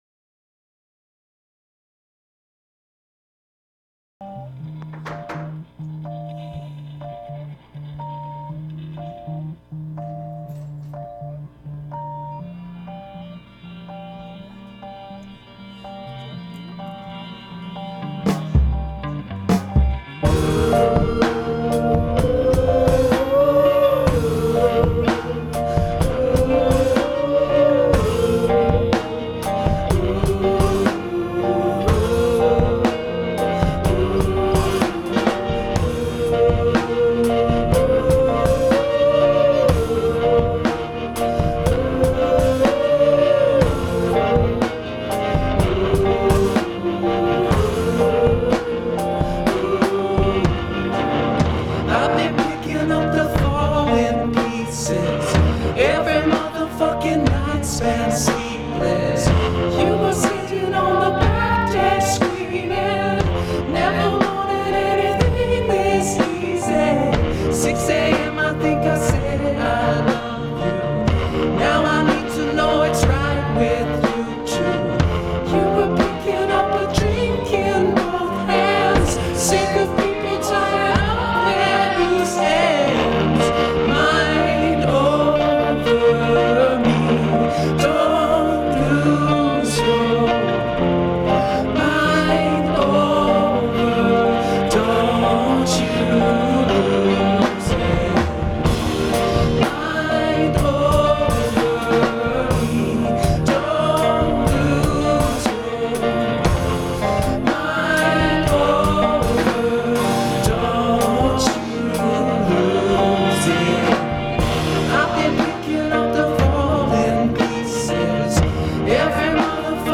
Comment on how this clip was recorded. I had an 8-track recorder.